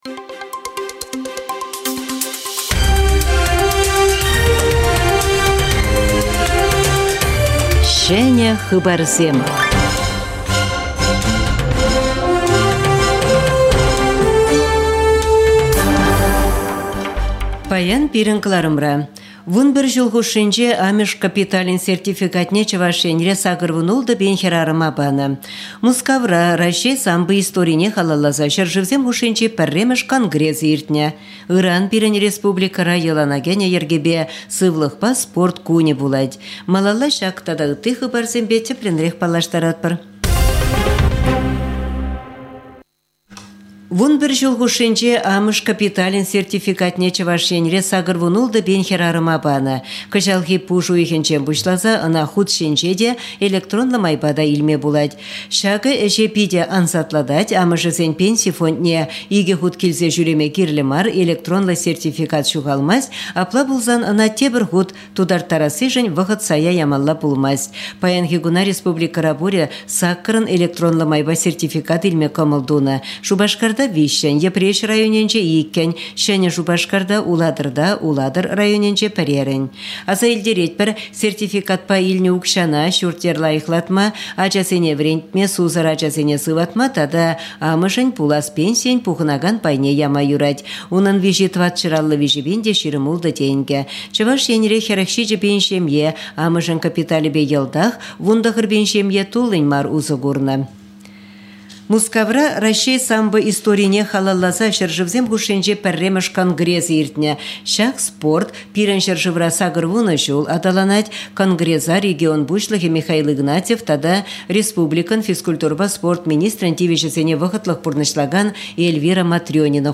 Выступление